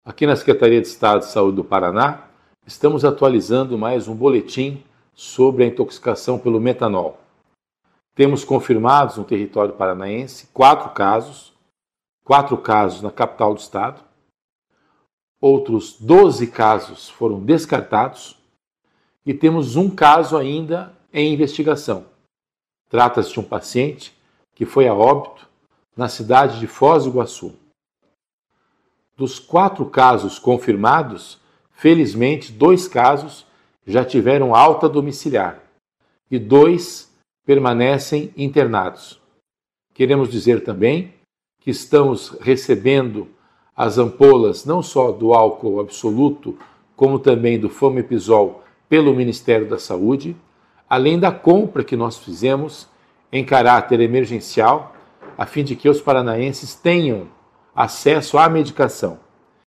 Sonora do secretário da Saúde em exercício, César Neves, sobre a atualização dos casos de intoxicação por metanol no Paraná